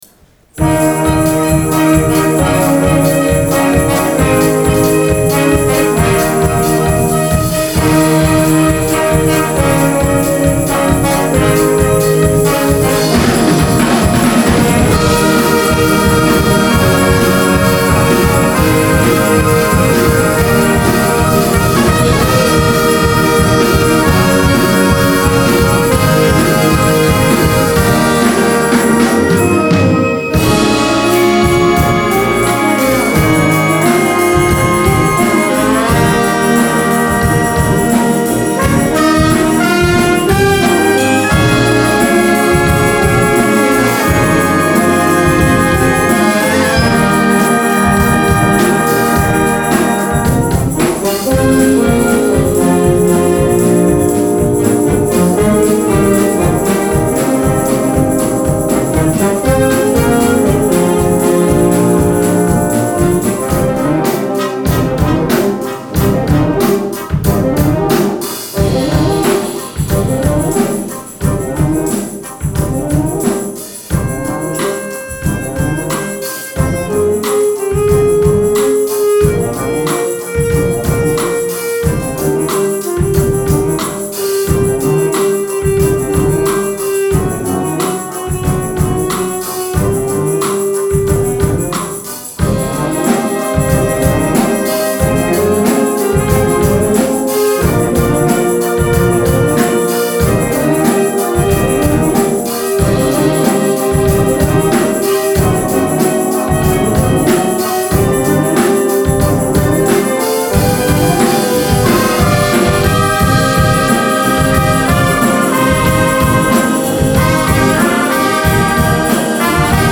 Op zaterdag 8 november 2014 is het themaconcert ‘Let me entertain you’ georganiseerd.
een combinatie van pop, film en musical.